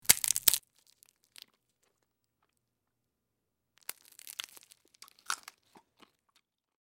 Chewing
Break Shell And Chew With Lip Smack And Close Bite, X4